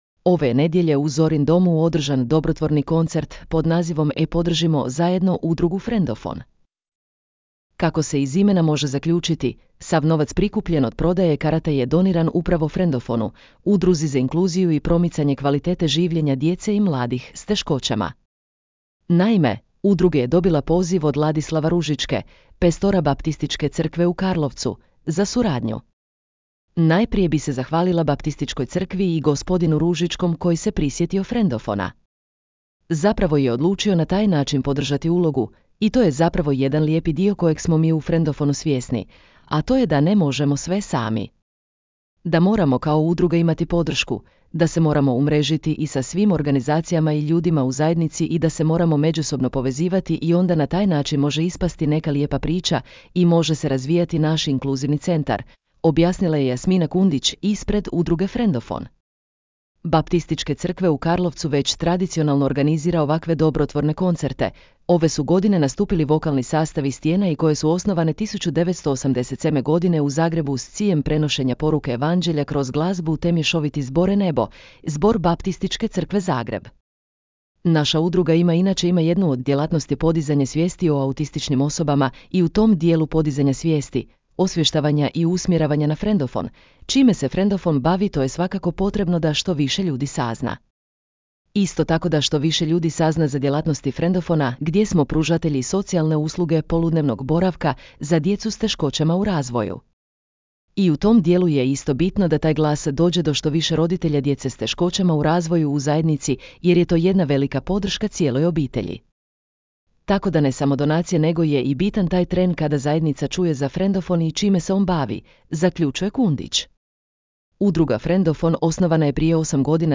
Ove je nedjelje u Zorin domu održan dobrotvorni koncert pod nazivom “Podržimo zajedno Udrugu Frendofon”.